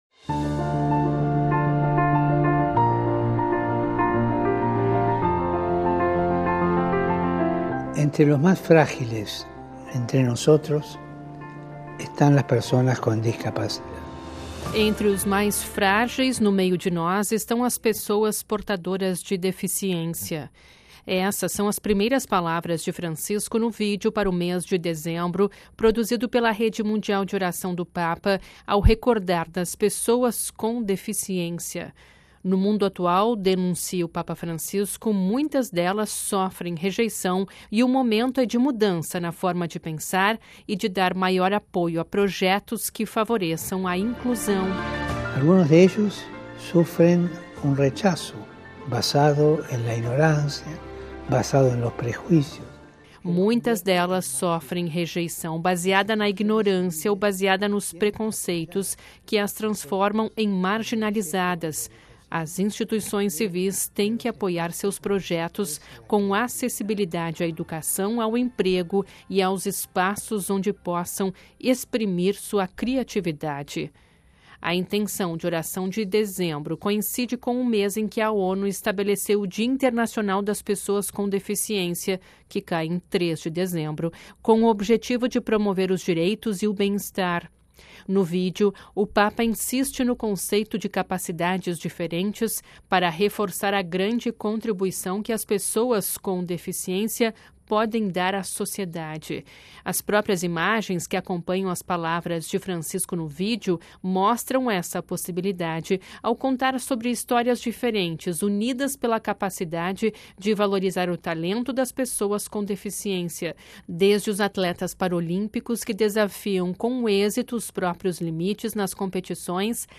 Ouça a reportagem com a voz do Papa e compartilhe